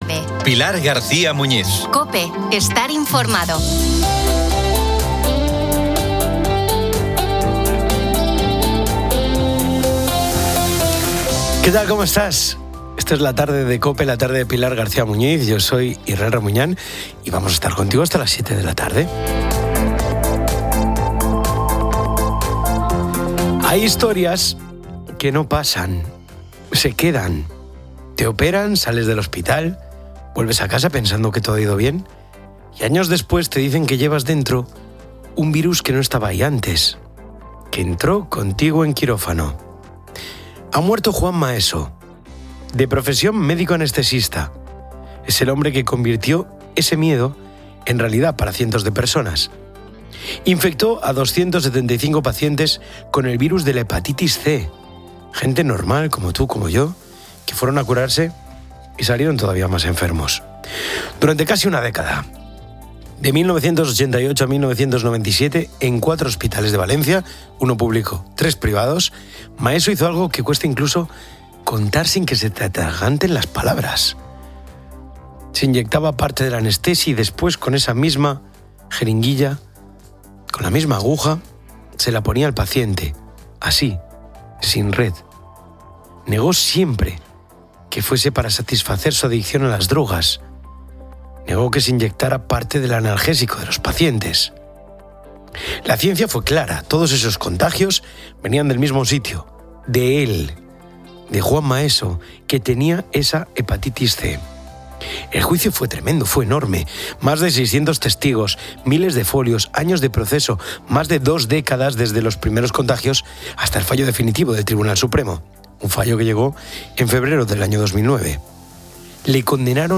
Se analiza la escalada de tensión en Oriente Medio, con Estados Unidos criticando a aliados como España por su falta de apoyo en el conflicto con Irán, e Israel anunciando demoliciones en Líbano. Una residente de Beirut relata la difícil realidad de vivir en un país en guerra constante, mientras expertos debaten la política israelí y la ineficacia global.
Una anestesista critica la ley española de eutanasia, la carencia de cuidados paliativos y la ética médica. Finalmente, se destaca el auge de las compras de segunda mano y su papel en la economía circular.